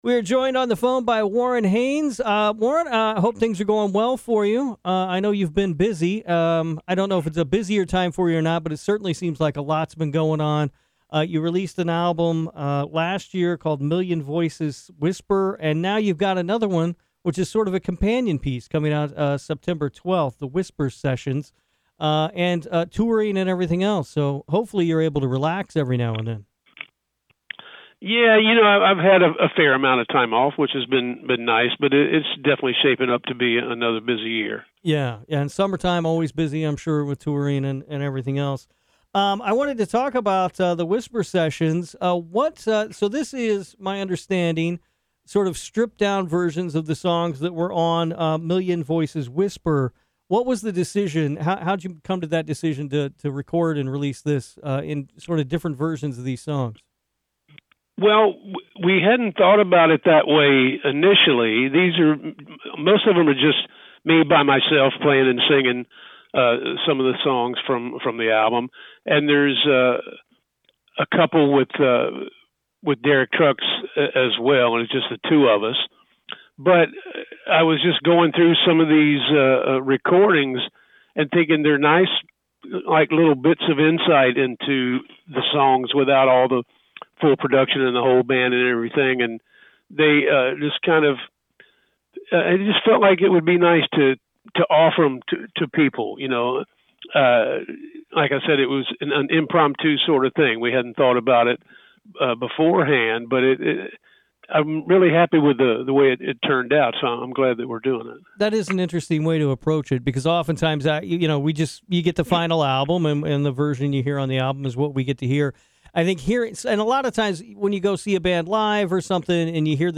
Warren Haynes Interview